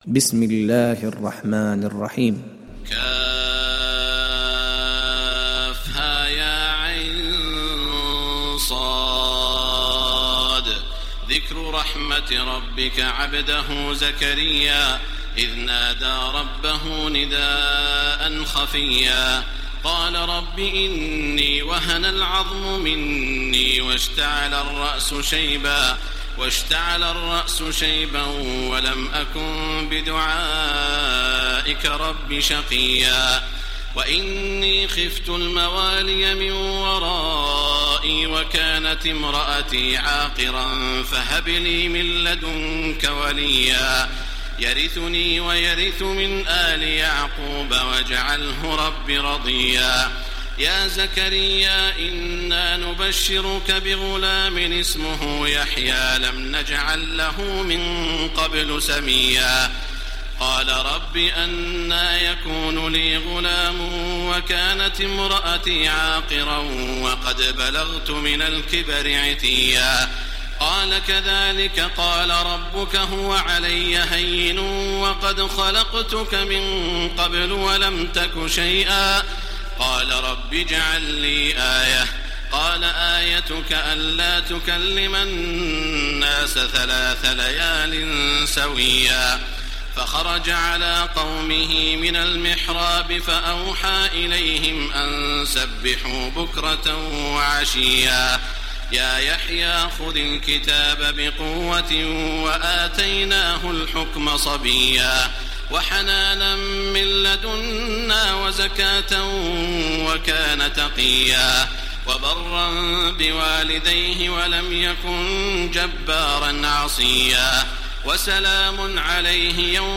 تحميل سورة مريم mp3 بصوت تراويح الحرم المكي 1430 برواية حفص عن عاصم, تحميل استماع القرآن الكريم على الجوال mp3 كاملا بروابط مباشرة وسريعة
تحميل سورة مريم تراويح الحرم المكي 1430